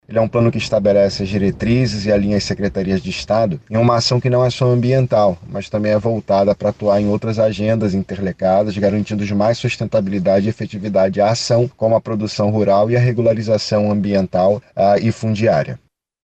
Além de reduzir o avanço desses crimes ambientais, a iniciativa visa incentivar o uso sustentável dos recursos naturais e o ordenamento territorial, com ênfase em áreas críticas, como explica o secretário de Estado do Meio Ambiente, Eduardo Taveira.
Sonora-1-Eduardo-Taveira-secretario-de-Meio-Ambiente-do-Amazonas.mp3